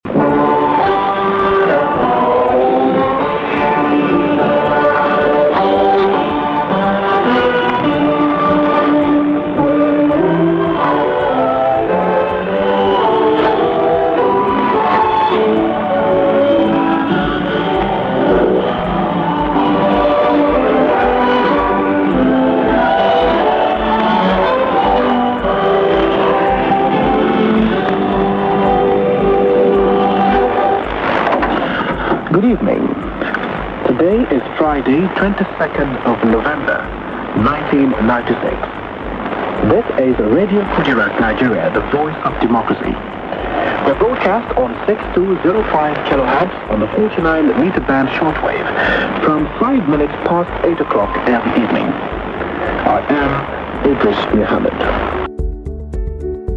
Radio Kudirat Nigeria - 1996, 1997, Interval Signal / Station ID Audio